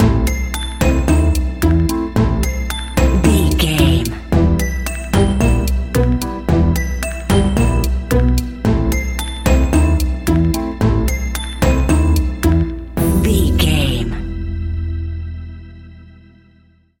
Aeolian/Minor
eerie
piano
drums
synthesizer
spooky
horror music
Horror Synths